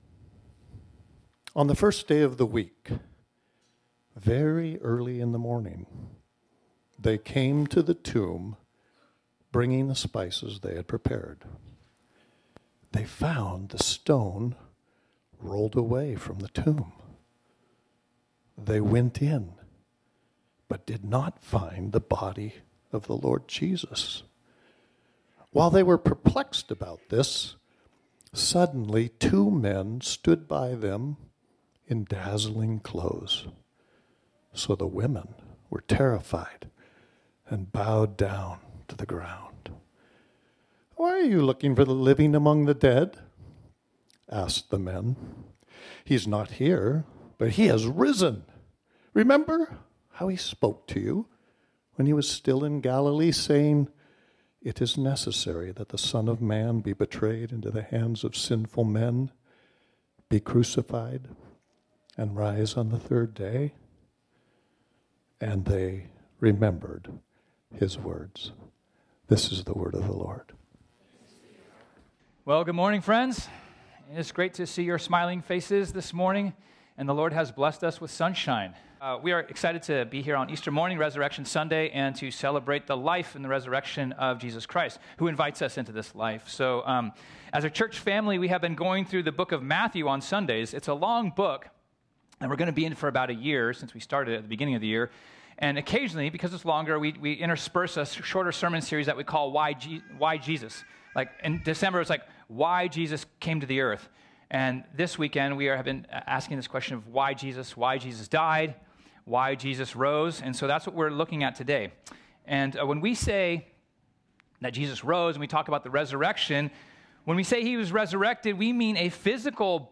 This sermon was originally preached on Sunday, March 31, 2024.